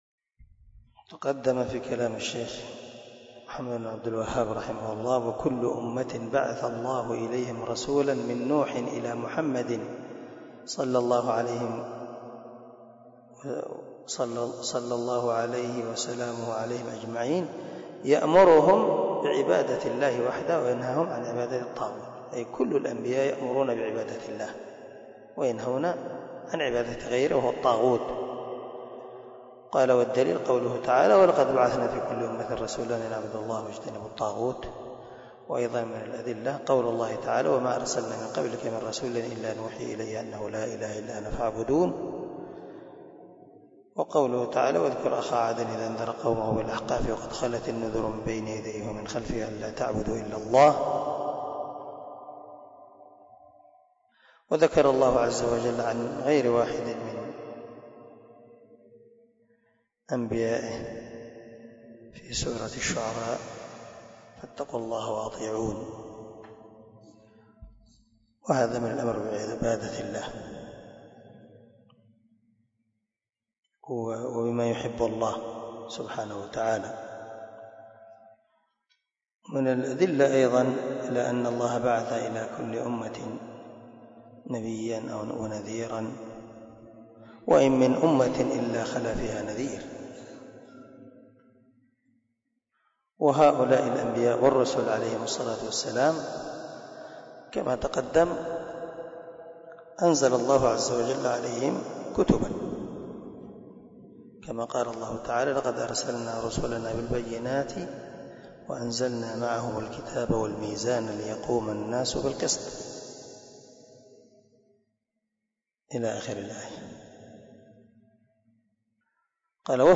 🔊 الدرس 39 من شرح الأصول الثلاثة